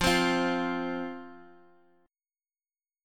F5 chord